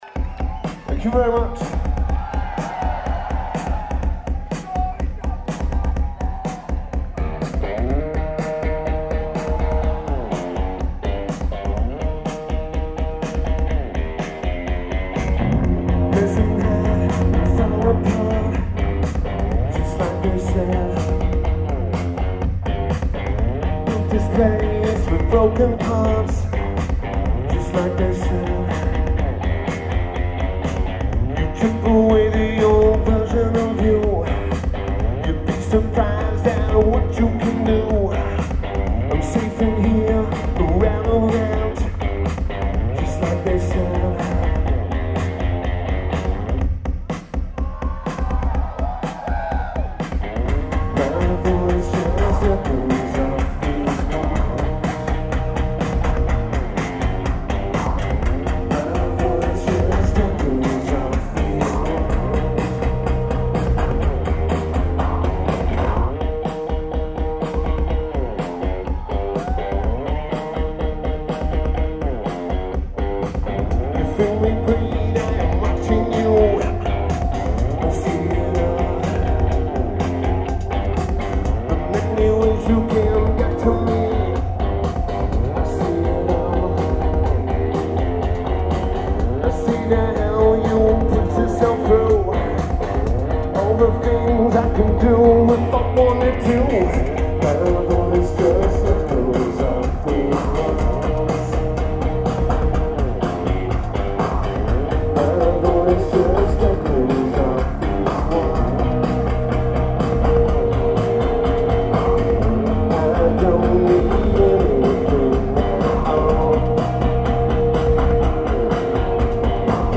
Studio Coast
Tokyo Japan
Lineage: Audio - AUD (CSBs + Edirol R09)